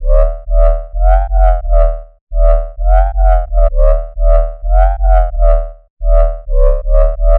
Although other sounds accompany it as the measures pass, the main phrase is actually a pre-made bassline straight from RA Ultimate Garage & 2 Step Vol. 2. There is some processing on the sound, as the original one is pretty weak, but when you listen to the song, and the sample, you’ll see that they are the same.